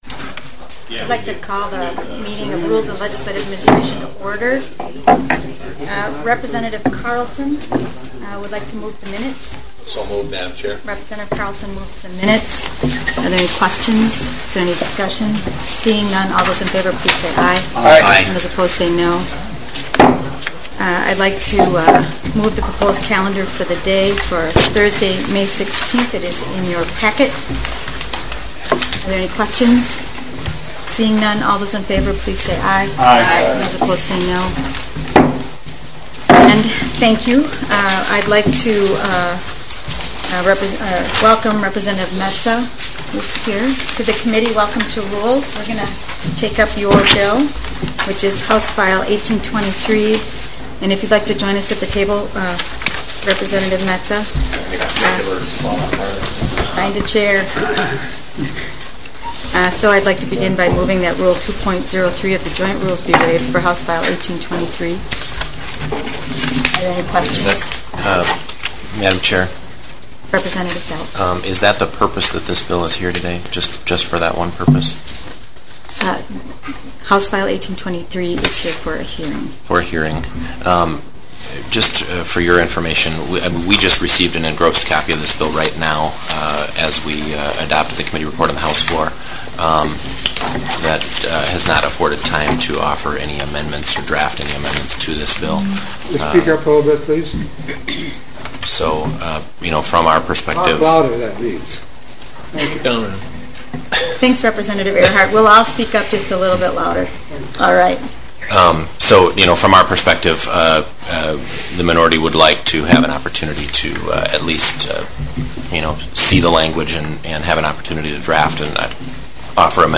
Joint meeting of the House Government Operations Committee, and the Senate State and Local Government Committee
Panel Discussion of state agency and private sector representatives familiar with rulemaking process.